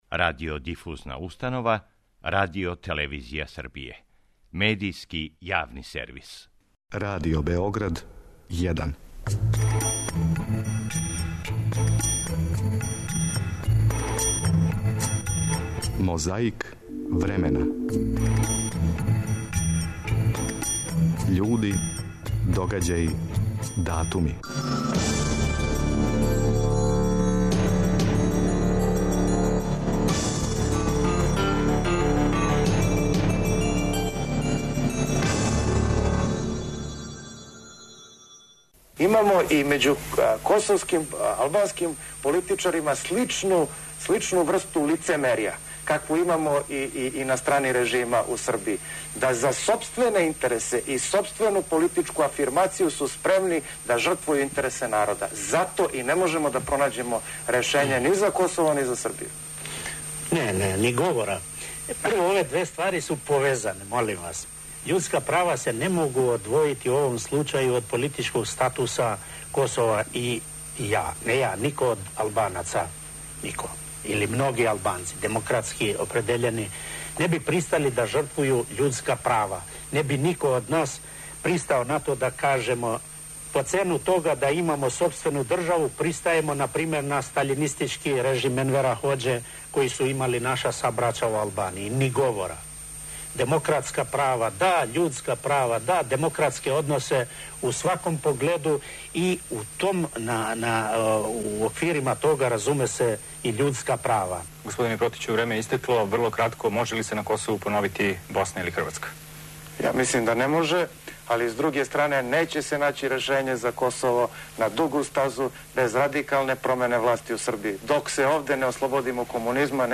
4. априла 1991. године у Београду је одржана конференција за новинаре поводом завршетка другог састанка шесторице републичких председника.
Подсећа на прошлост (културну, историјску, политичку, спортску и сваку другу) уз помоћ материјала из Тонског архива, Документације и библиотеке Радио Београда.